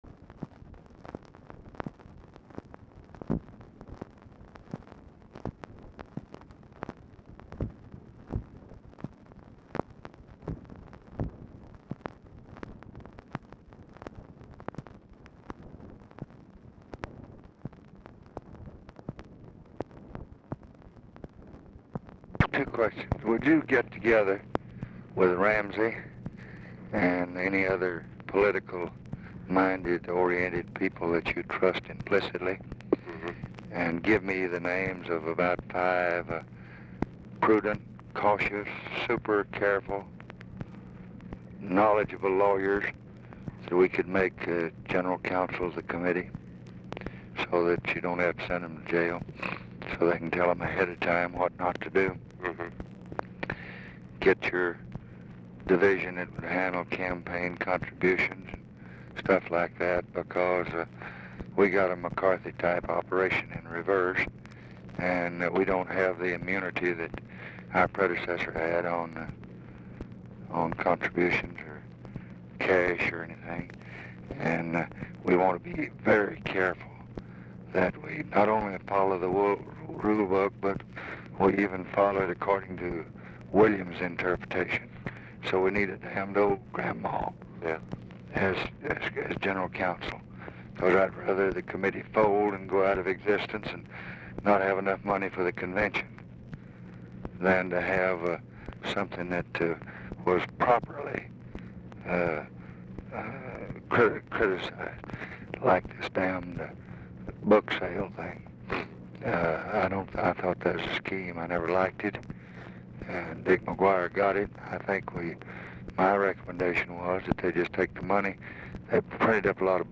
Telephone conversation # 10059, sound recording, LBJ and NICHOLAS KATZENBACH, 4/29/1966, 12:59PM | Discover LBJ
RECORDING STARTS AFTER CONVERSATION HAS BEGUN
LBJ IS SLIGHTLY HOARSE
Format Dictation belt
Location Of Speaker 1 Mansion, White House, Washington, DC